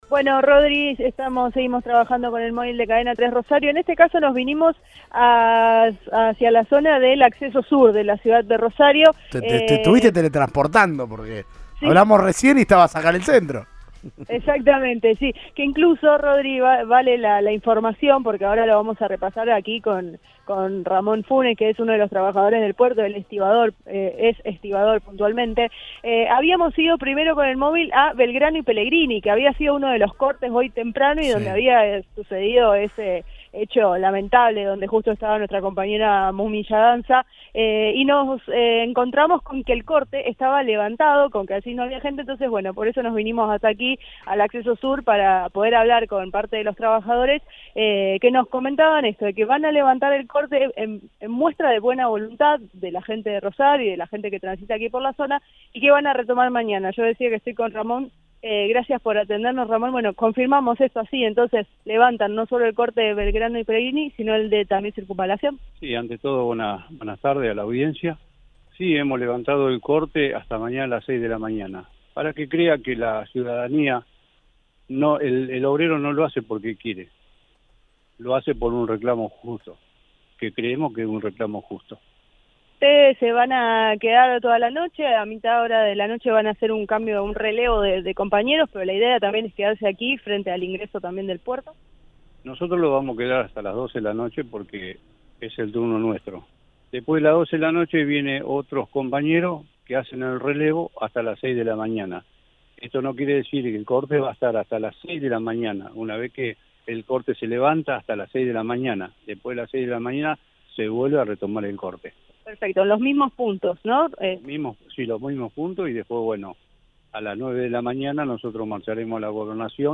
Audio. Corte de portuarios y tiros en vivo en plena cobertura de Cadena 3
Un suceso inusual tuvo lugar este lunes cerca de las 7 en una cobertura en vivo del móvil de Cadena 3 Rosario, en Radioinforme 3: en el corte de tránsito de los portuarios, un hombre sacó un arma y disparó dos veces.